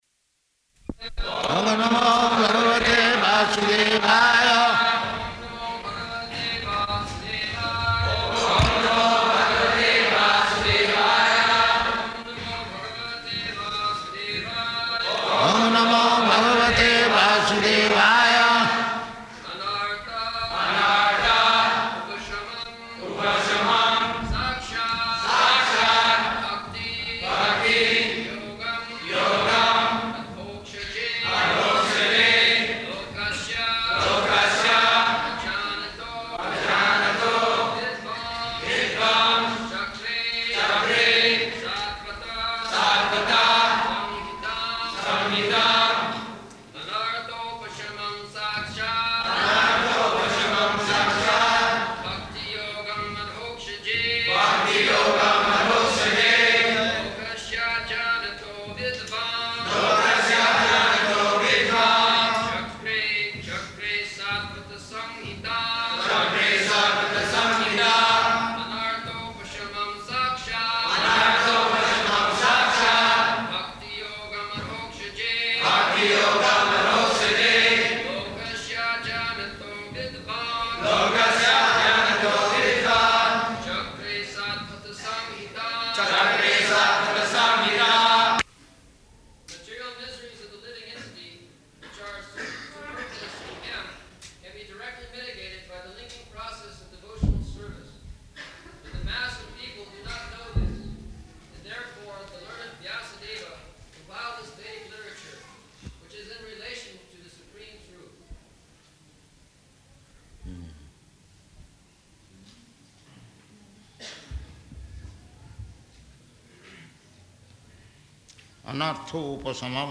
-- Type: Srimad-Bhagavatam Dated: April 18th 1975 Location: Vṛndāvana Audio file
[Prabhupāda and devotees repeat] [leads chanting of verse, etc.]